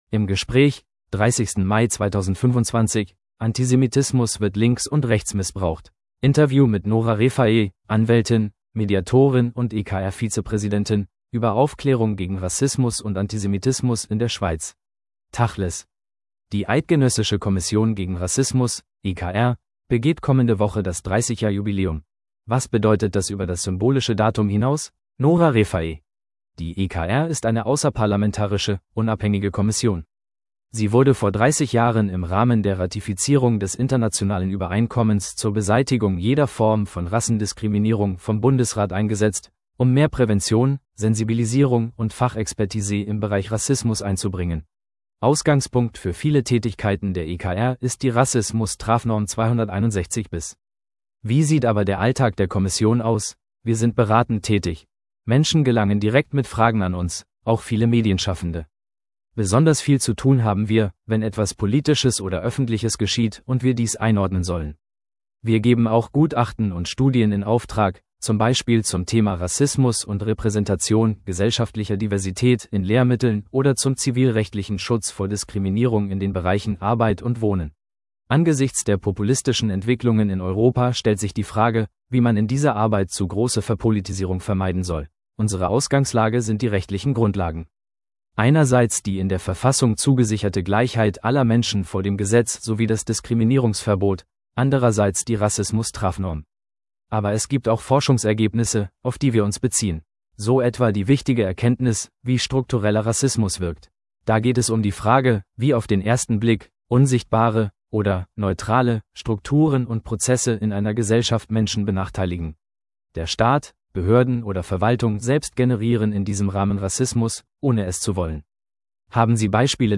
im Gespräch 30.